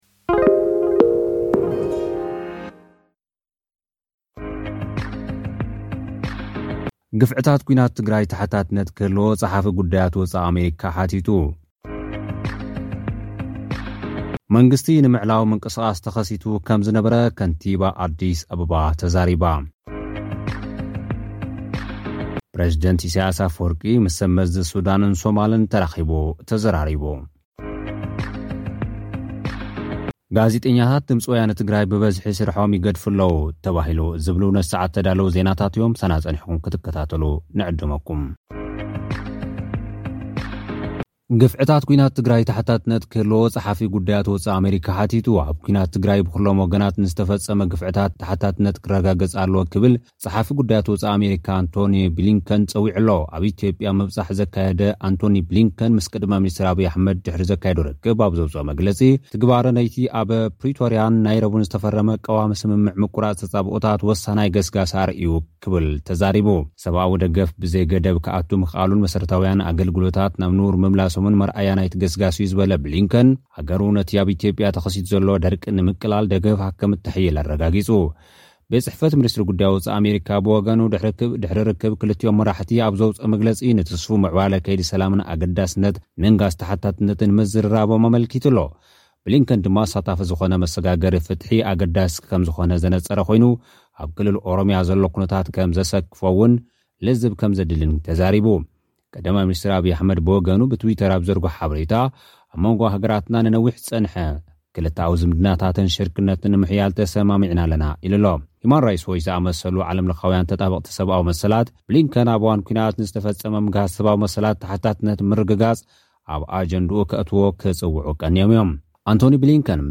ግፍዕታት ኲናት ትግራይ ተሓታትነት ክህልዎ ጸሓፊ ጉዳያት ወጻኢ ኣመሪካ ሓቲቱ። (ጸብጻብ)